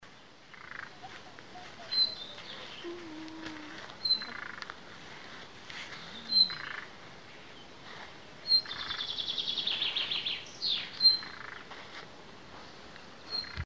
Putni -> Mežastrazdi ->
Lakstīgala, Luscinia luscinia
Administratīvā teritorijaPriekuļu novads
StatussUztraukuma uzvedība vai saucieni (U)